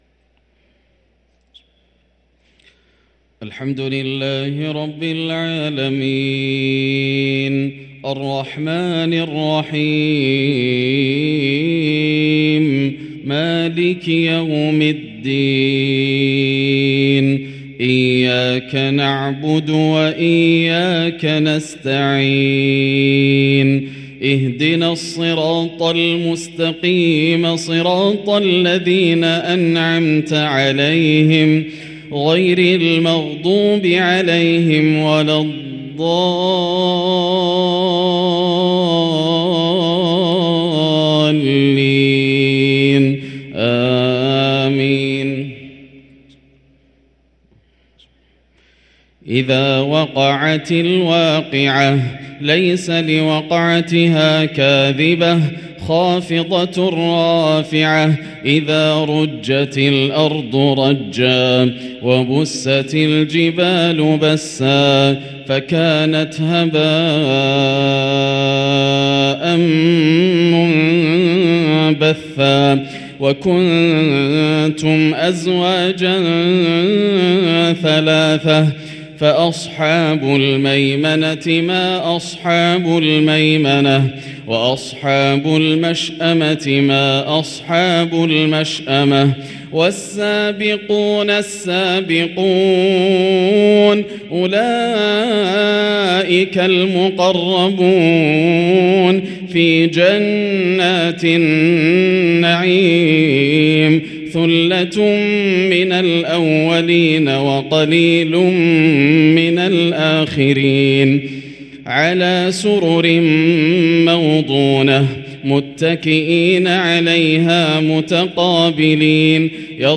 صلاة العشاء للقارئ ياسر الدوسري 24 رجب 1444 هـ
تِلَاوَات الْحَرَمَيْن .